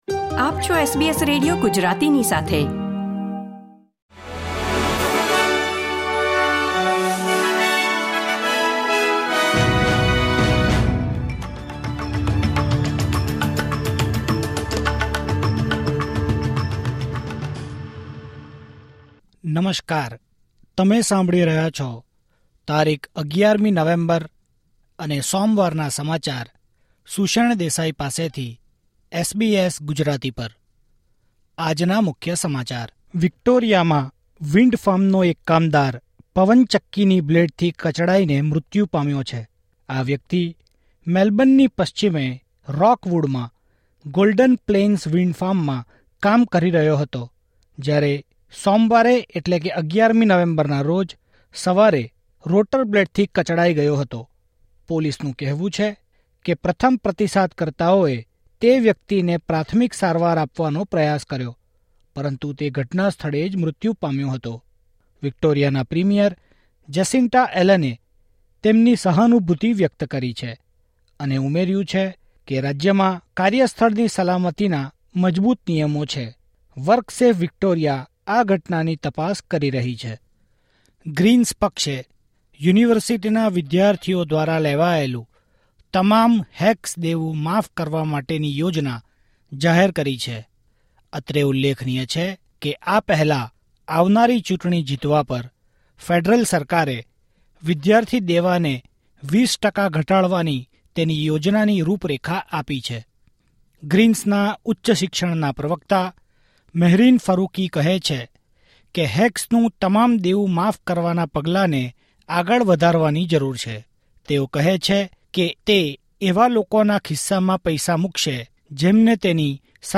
SBS Gujarati News Bulletin 11 November 2024